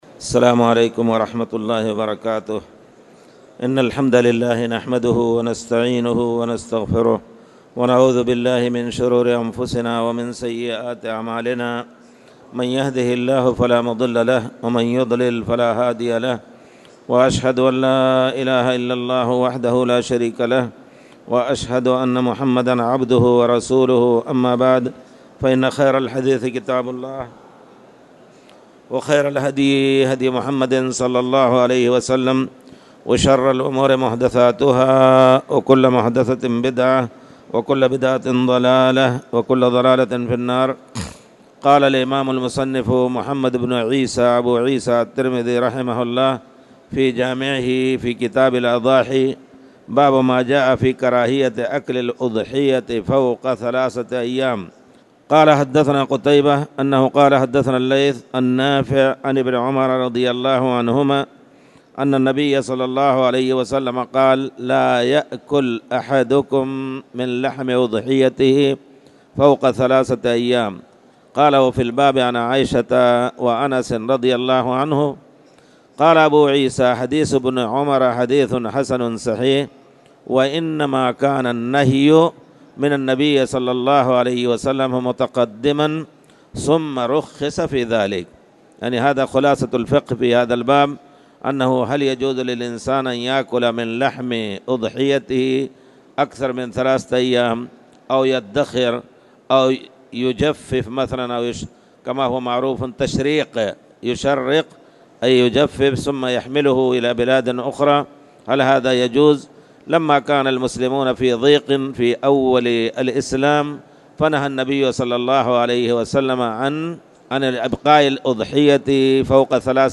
تاريخ النشر ٢٣ جمادى الآخرة ١٤٣٨ هـ المكان: المسجد الحرام الشيخ